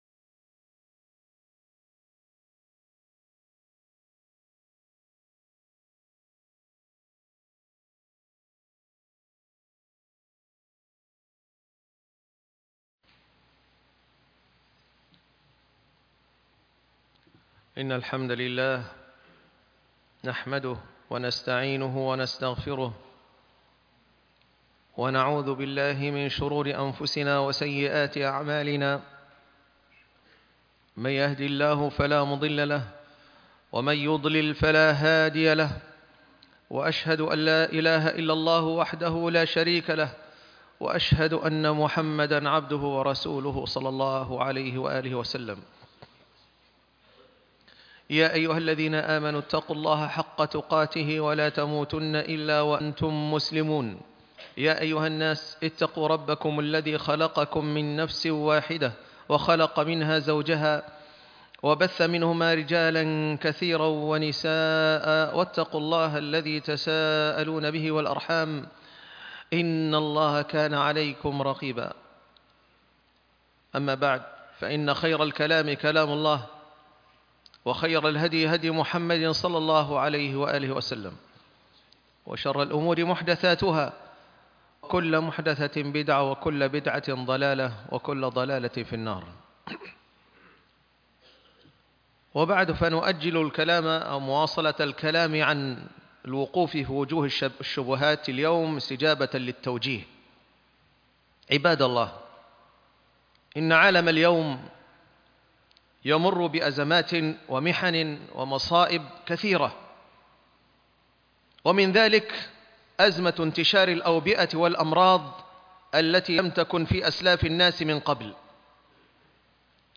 الأوبئة والعلاج الشرعي - خطبة الجمعة